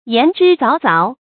言之鑿鑿 注音： ㄧㄢˊ ㄓㄧ ㄗㄠˊ ㄗㄠˊ 讀音讀法： 意思解釋： 鑿鑿：確實。話說得非常確實。